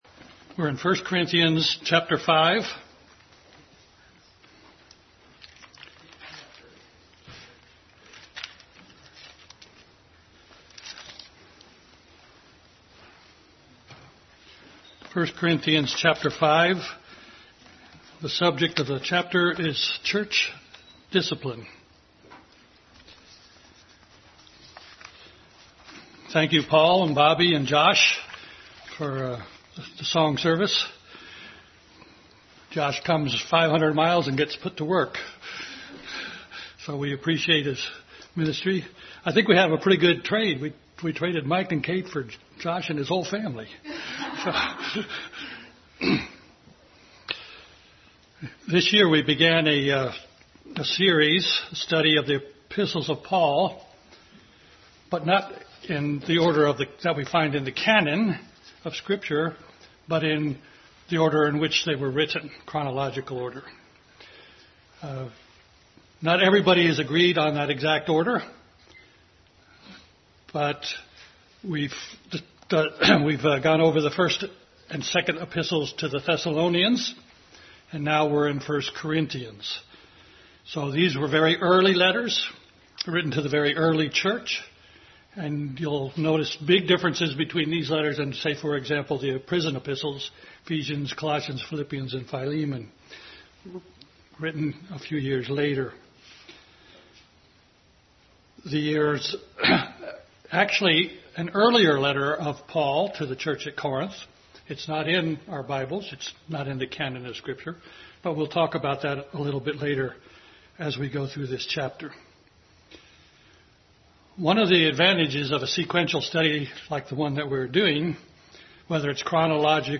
Bible Text: 1 Corinthians 5:1-13, 1 Timothy 1:8-11, Galatians 6:1, Leviticus 23, Exodus 12 & 13, Romans 8:20-28 | Adult Sunday School class continued study in 1 Corinthians.